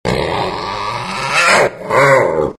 Звуки черной пантеры
Недовольство пантеры проявляется так